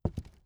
ES_Walk Wood Creaks 6.wav